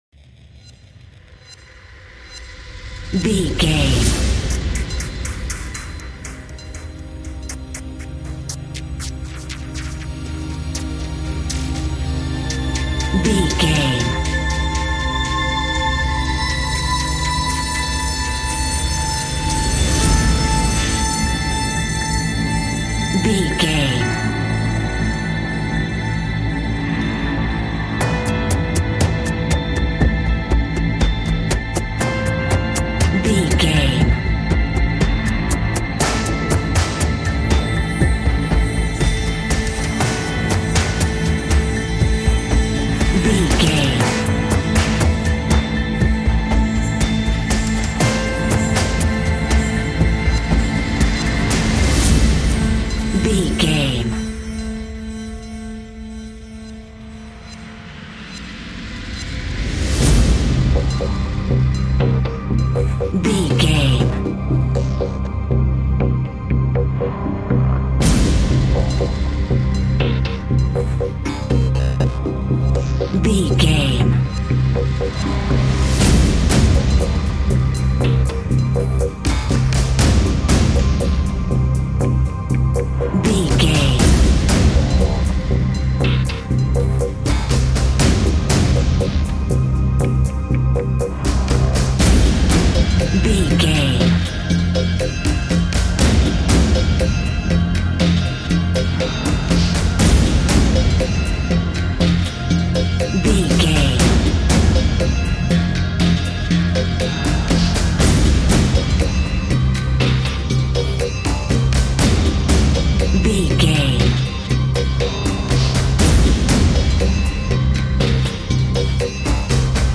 Ionian/Major
industrial
dark ambient
experimental
synths